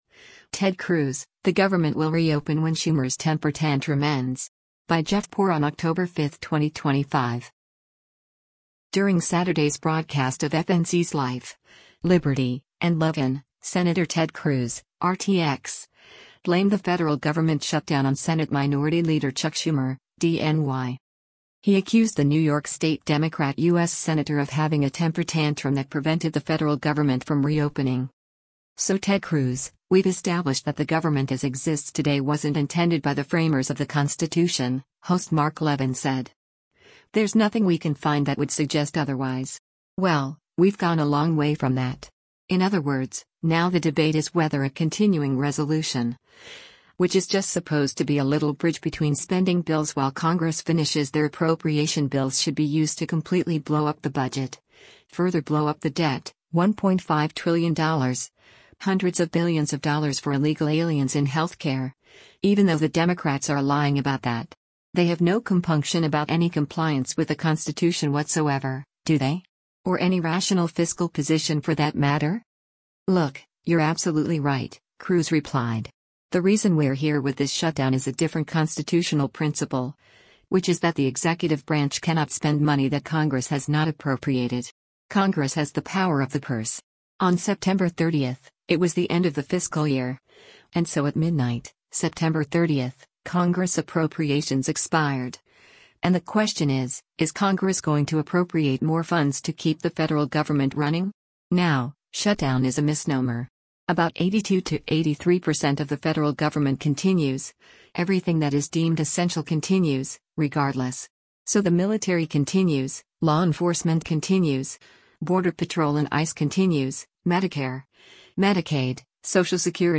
During Saturday’s broadcast of FNC’s “Life, Liberty & Levin,” Sen. Ted Cruz (R-TX) blamed the federal government shutdown on Senate Minority Leader Chuck Schumer (D-NY).